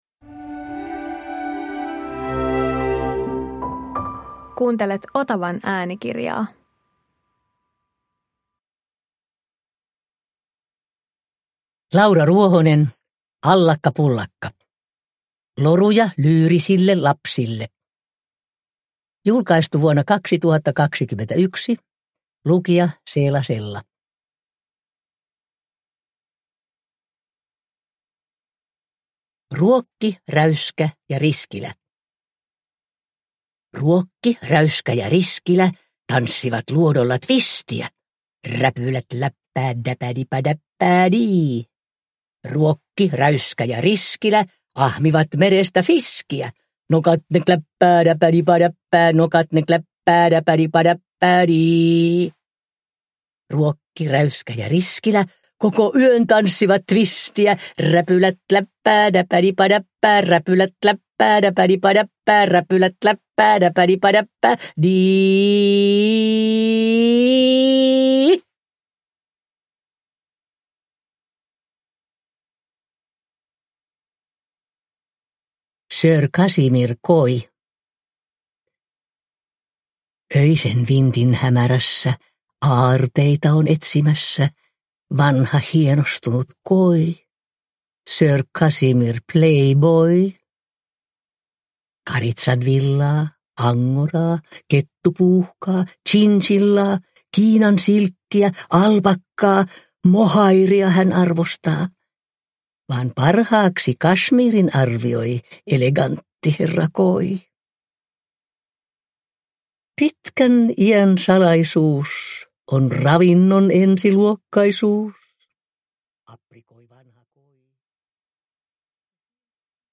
Allakka pullakka – Ljudbok – Laddas ner
Iki-ihanat, niin lasten kuin kriitikoidenkin ylistämät runot julkaistaan Seela Sellan tulkitsemana äänikirjana.
Uppläsare: Seela Sella